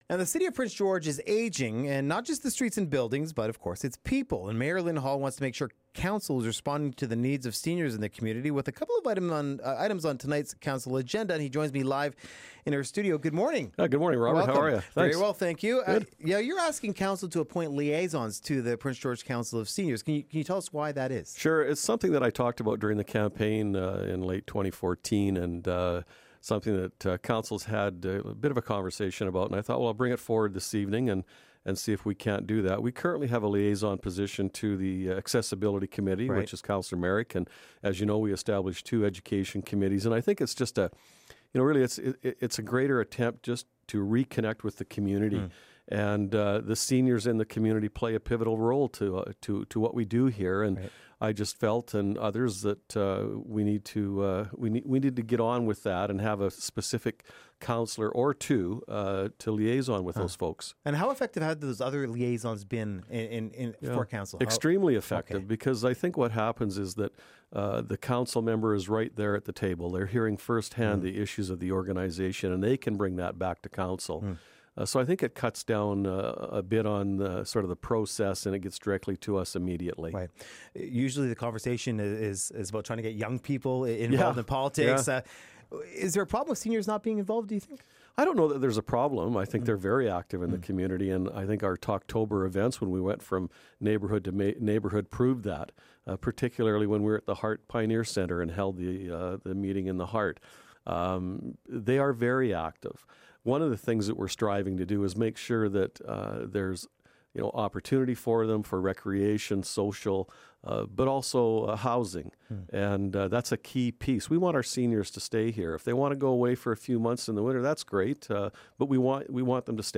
According to the latest numbers from the province, the city of Prince George is shrinking- and it's getting older. We speak to mayor Lyn Hall about how city hall is responding.